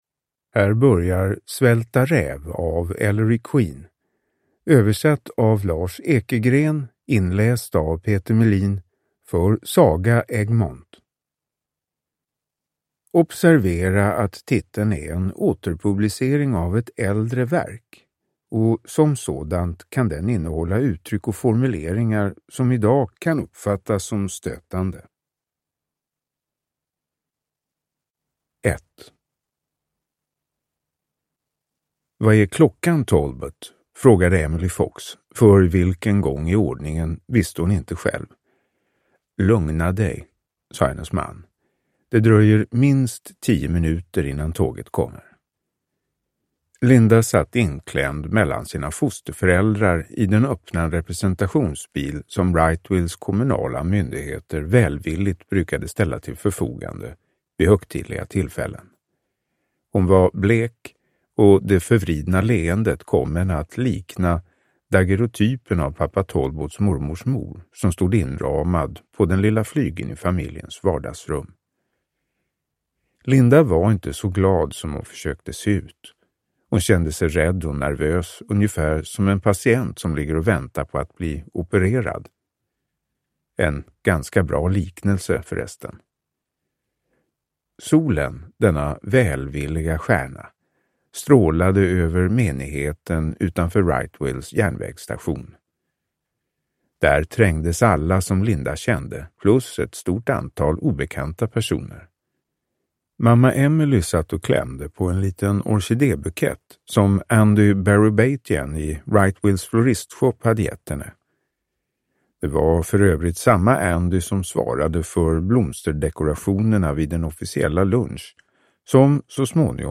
Svälta räv – Ljudbok